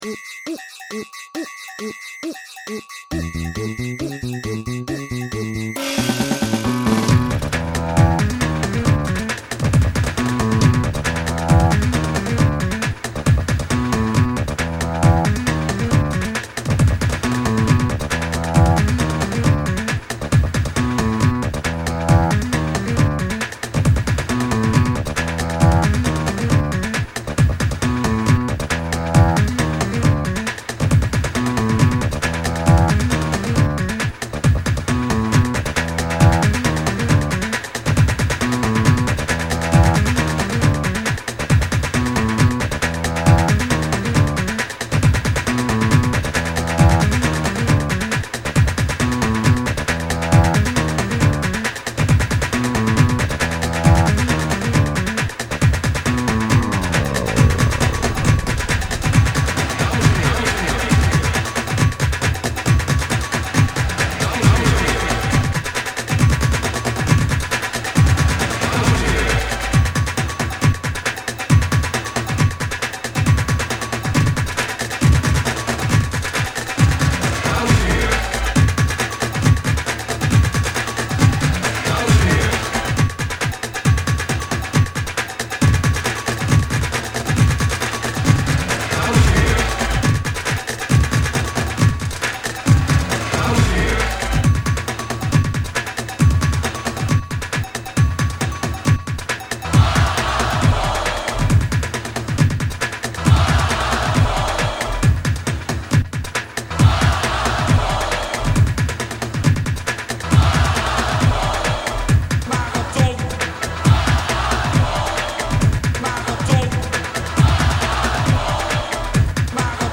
Den Haag, mijn 's-Gravenhage: Liefdeslied voor een prachtig oud wijf
A'- en R'damse liederen in langzamewalstempo over Jordaan en Haven, de Hollandse Fado, za'k maar zeggen.
In de huisstudio
accordeons, piano's en orgels
klarinetje d'r op